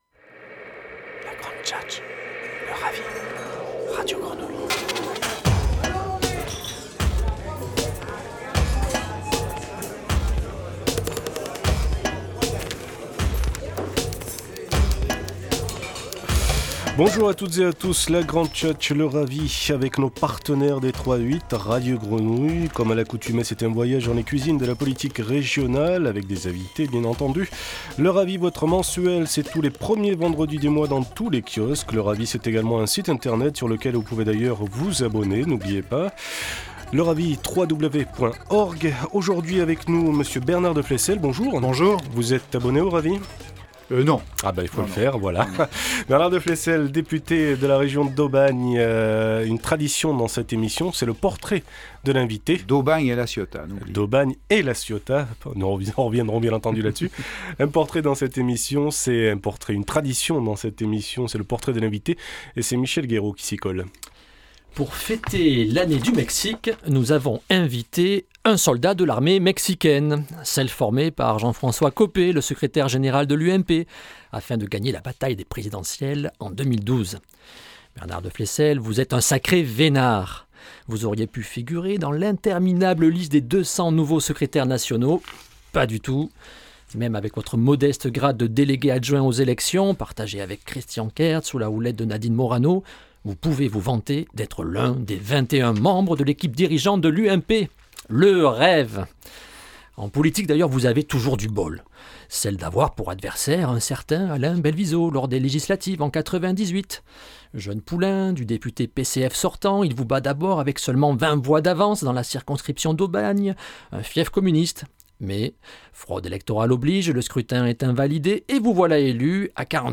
Bernard Deflesselles, député UMP des Bouches-du-Rhône, invité de la Grande Tchatche
Entretien radio en partenariat avec Radio Grenouille